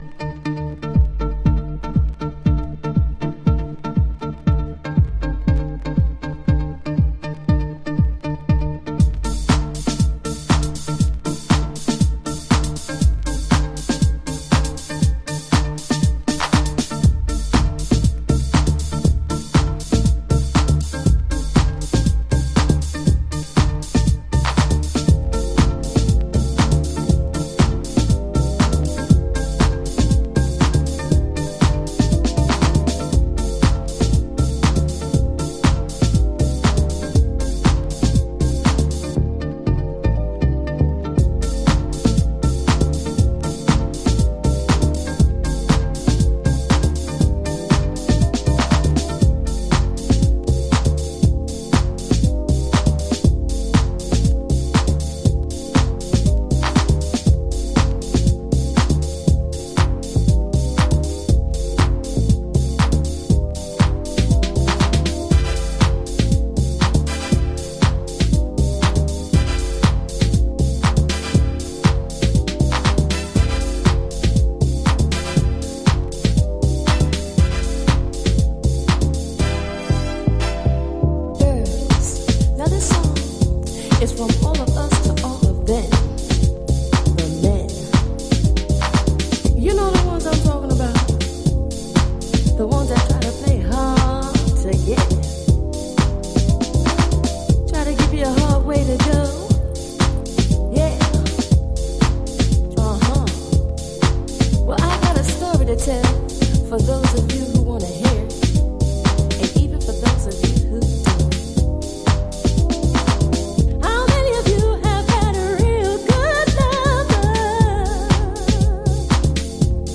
心に滲みるディープハウスオブソウル。